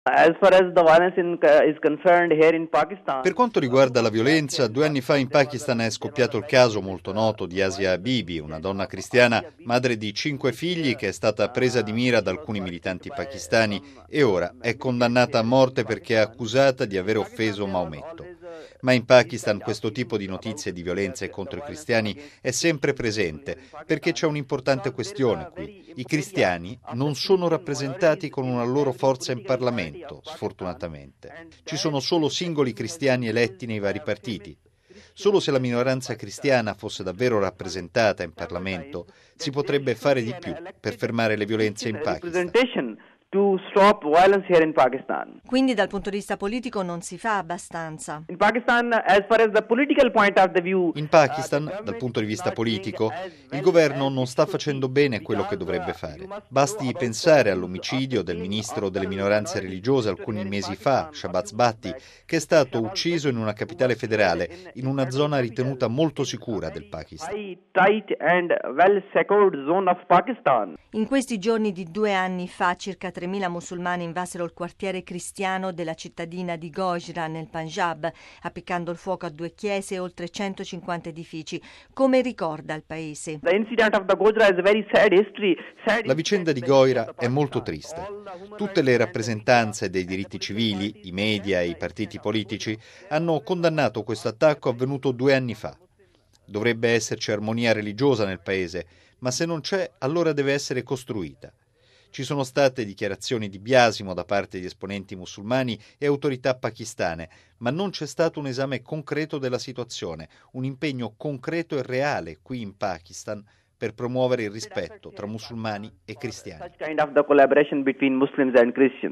ha parlato